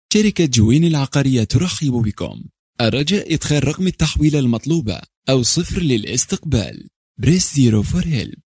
阿拉伯语翻译/阿语翻译团队成员主要由中国籍和阿拉伯语国家的中阿母语译员组成，可以提供证件类翻译（例如：驾照翻译、出生证翻译、房产证翻译，学位证翻译，毕业证翻译、成绩单翻译、无犯罪记录翻译、营业执照翻译、结婚证翻译、离婚证翻译、户口本翻译、奖状翻译、质量证书、许可证书等）、公证书翻译、病历翻译、公司章程翻译、技术文件翻译、工程文件翻译、合同翻译、审计报告翻译、视频听译/视频翻译、声音文件听译/语音文件听译等；阿拉伯语配音/阿语配音团队由阿拉伯语国家的阿拉伯语母语配音员组成，可以提供阿拉伯语专题配音、阿拉伯语广告配音、阿拉伯语教材配音、阿拉伯语电子读物配音、阿拉伯语产品资料配音、阿拉伯语宣传片配音、阿拉伯语彩铃配音等。
阿拉伯语样音试听下载